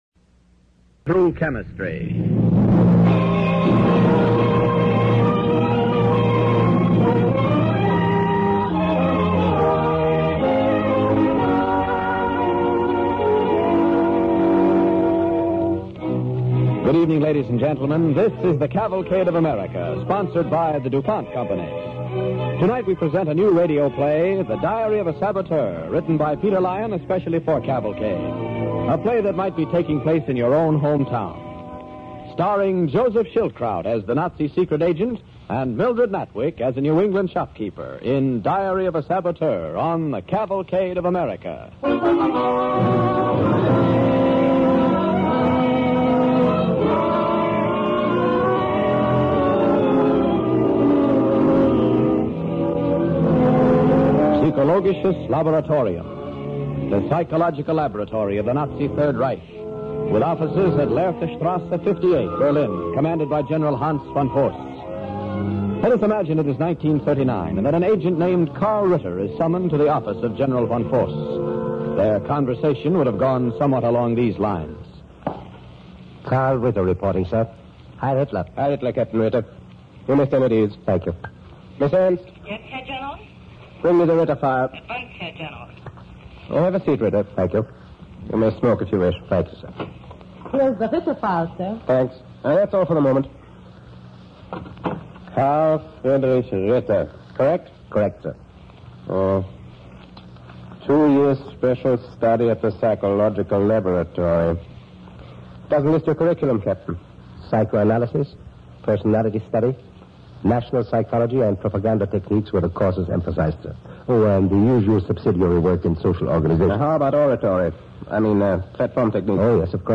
Diary of a Saboteur, starring Joseph Schildkraut